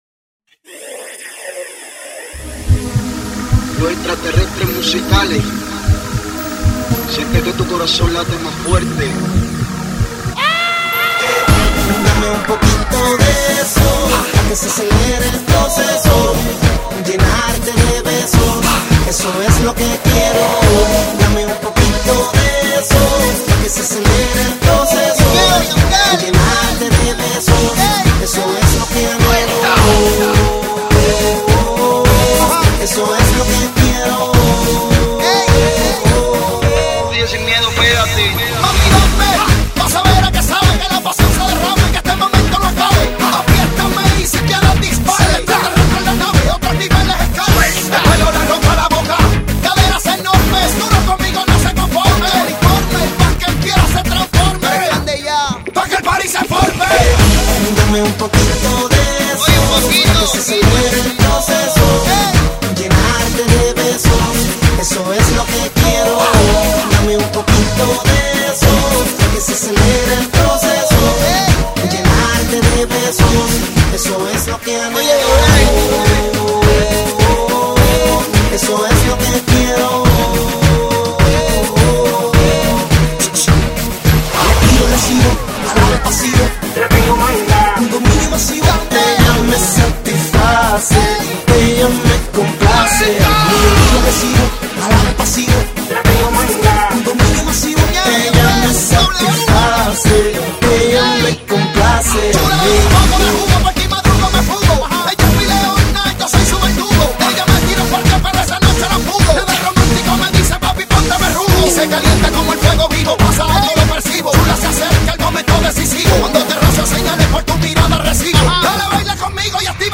来自卡耶伊 波多黎各的二人Reggaeton组合,
音乐类型：Reggaeton